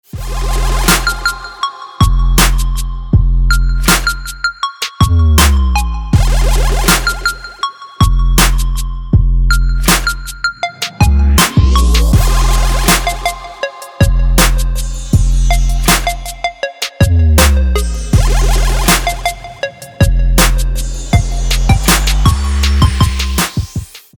• Качество: 320, Stereo
Trap
трэп